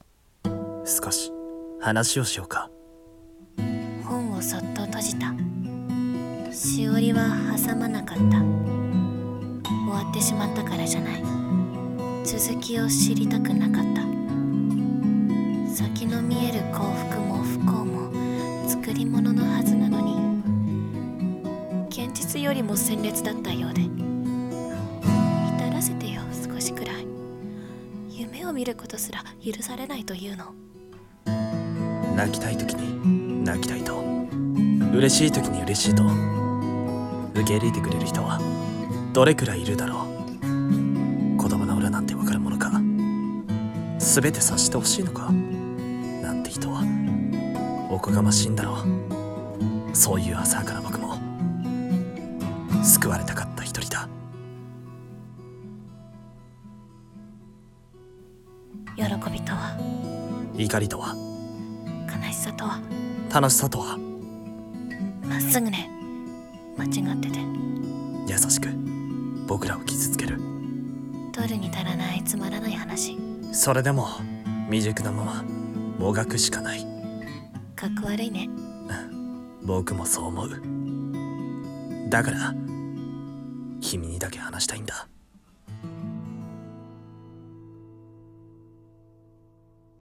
二人声劇【取るに足りない話】